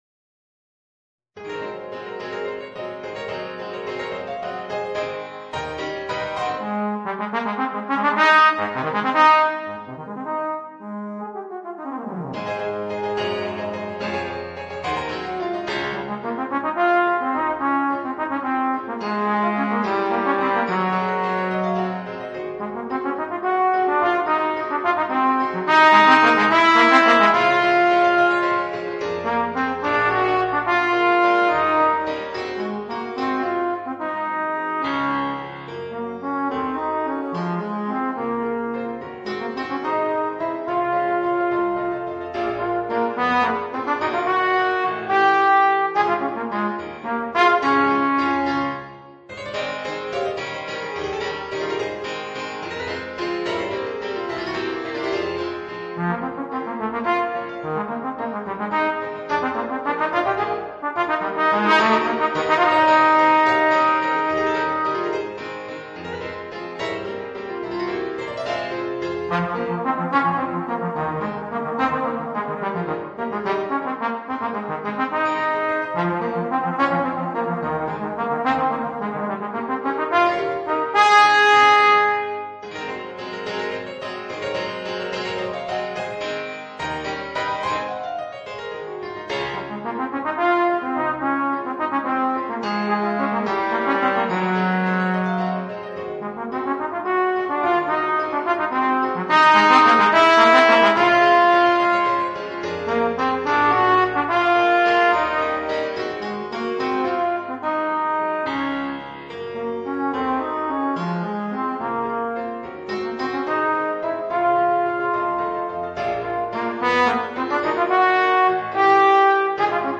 Voicing: Trombone and Piano or CD